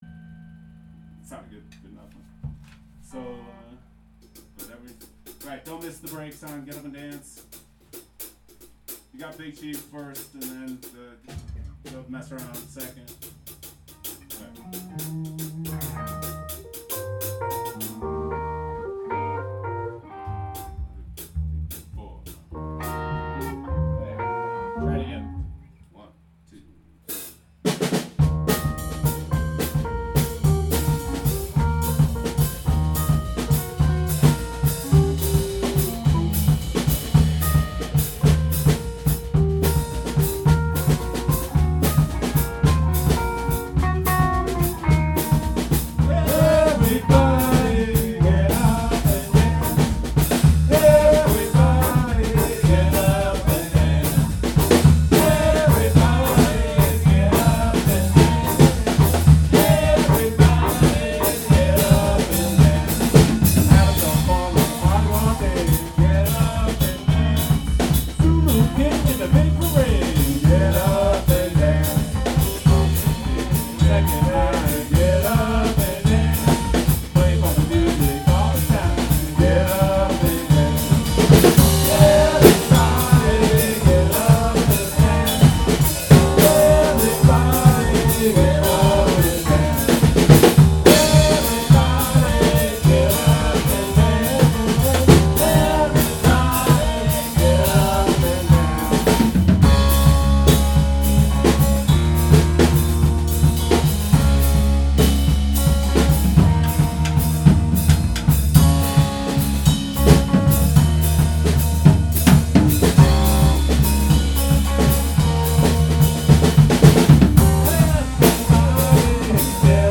Audio File: Live recording - oct 8 rehearsal (2/14/2026)